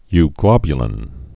(y-glŏbyə-lĭn)